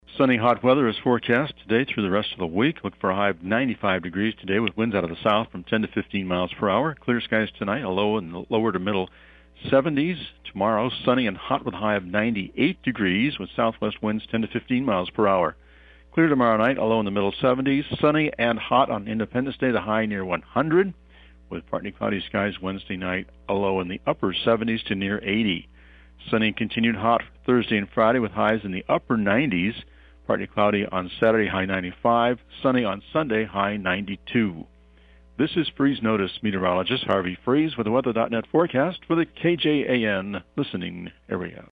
Here’s the (podcast) Freese-Notis forecast for Atlantic, and the KJAN listening area…